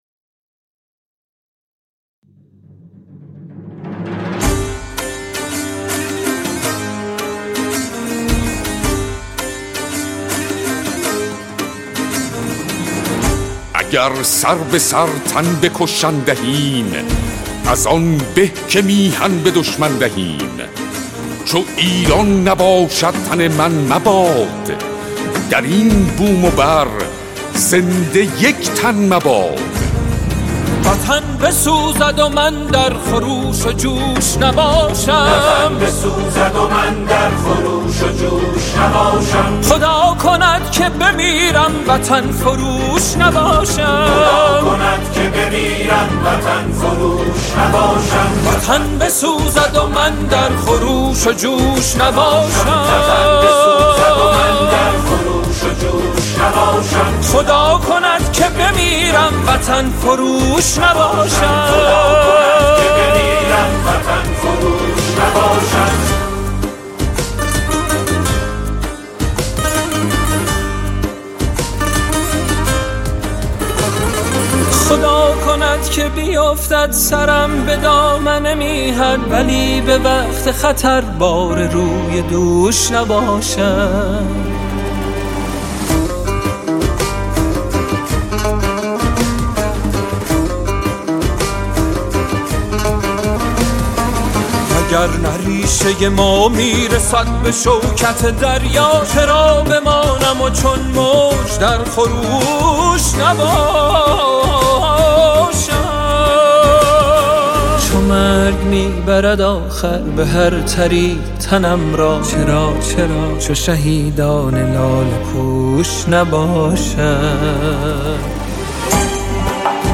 با صدای پرصلابت
ژانر: سرود